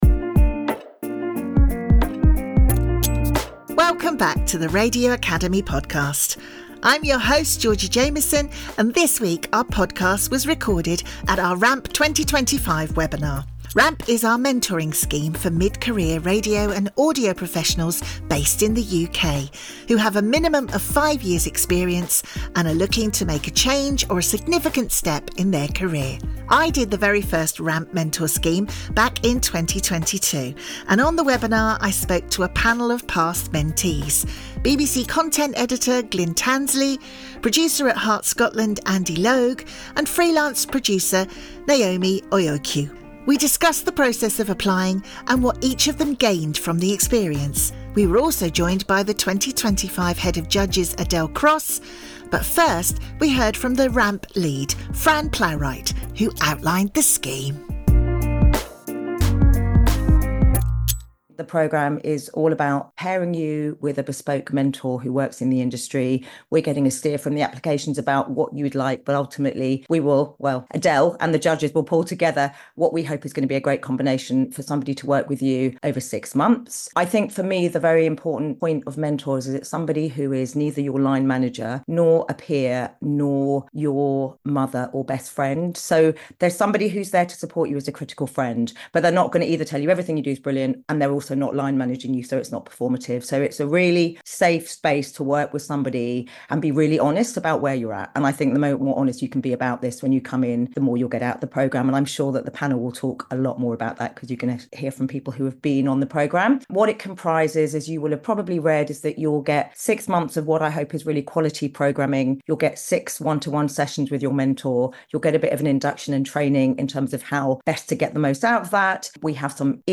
This week our podcast was recorded at our RAMP 2025 webinar. RAMP is our mentoring scheme for mid-career radio and audio professionals based in the UK, with a minimum of five years’ experience.